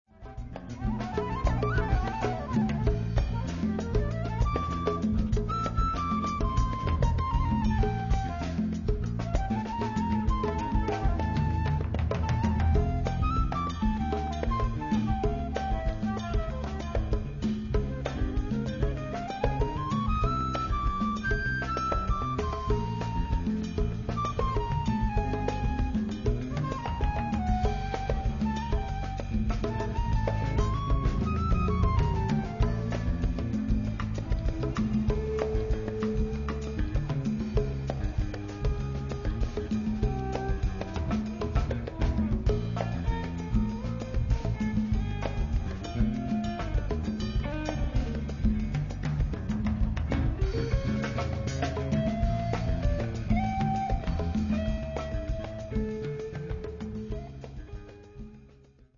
Guitars
Bass